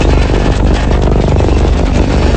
tyres_ripplestrip.wav